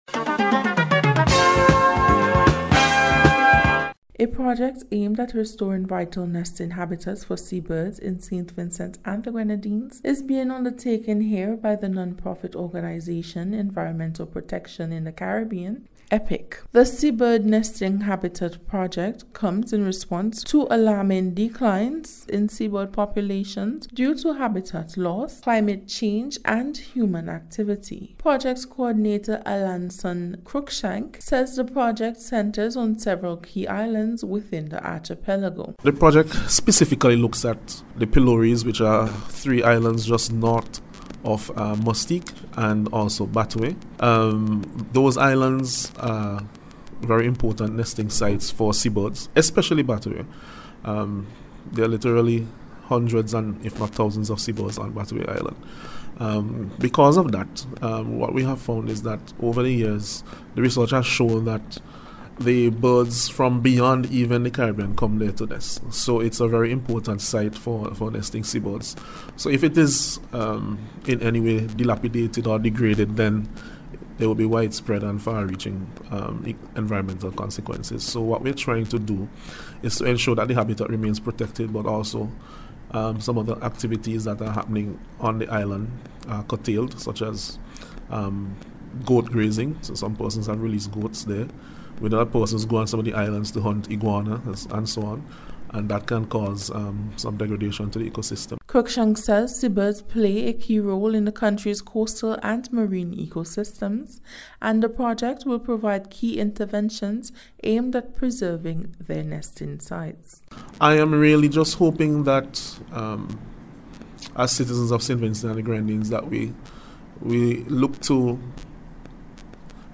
SEABIRD-RESTORATION-REPORT.mp3